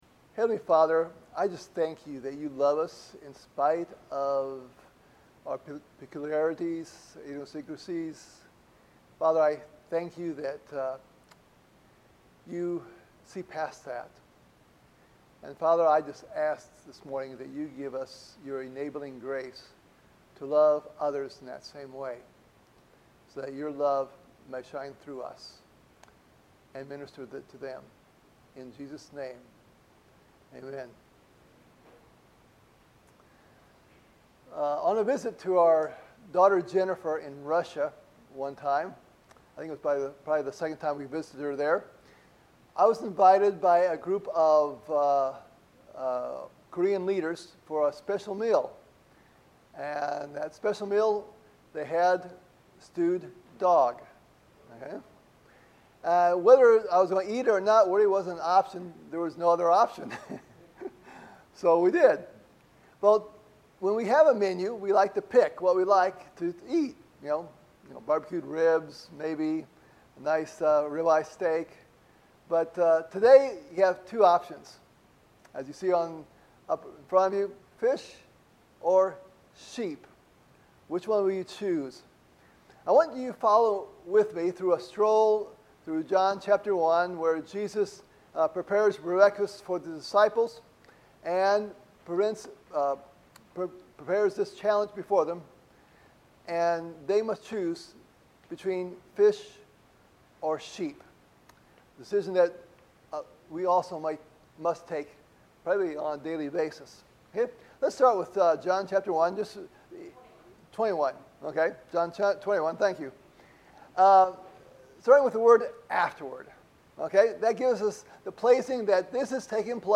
Visiting missionary from Ecuador